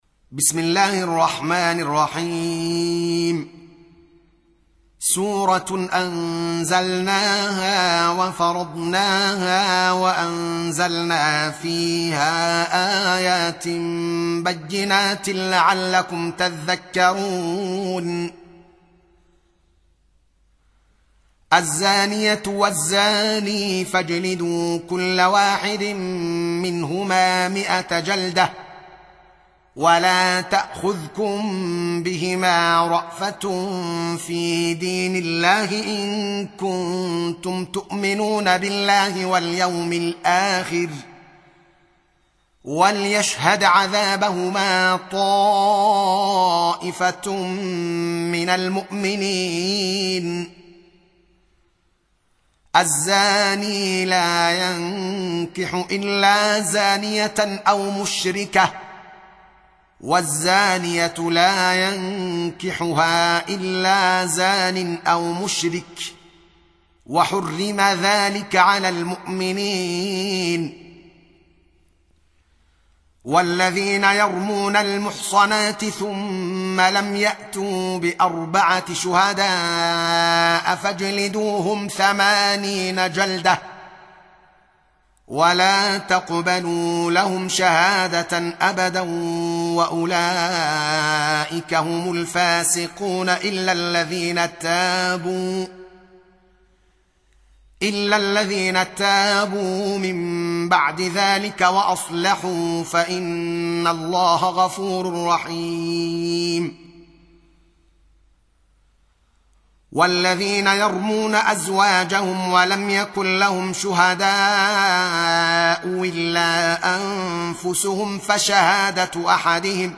Surah Repeating تكرار السورة Download Surah حمّل السورة Reciting Murattalah Audio for 24. Surah An-N�r سورة النّور N.B *Surah Includes Al-Basmalah Reciters Sequents تتابع التلاوات Reciters Repeats تكرار التلاوات